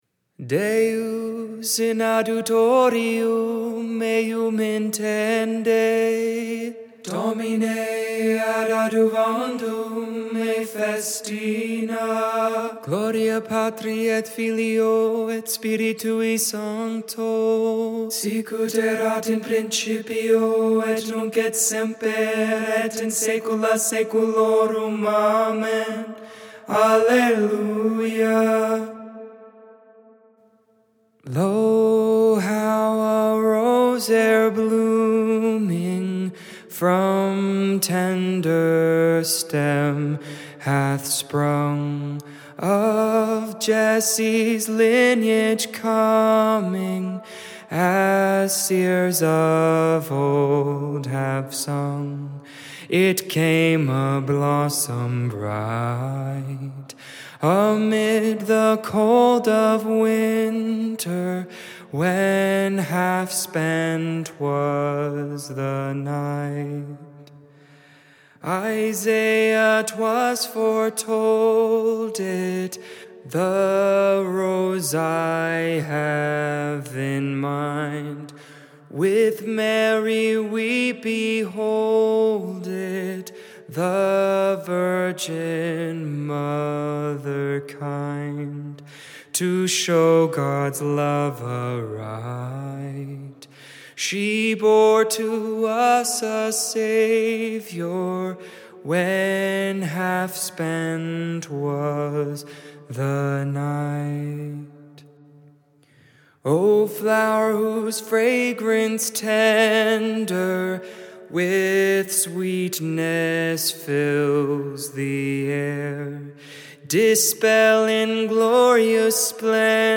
12.17.20 Vespers